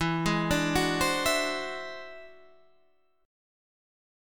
E Augmented 9th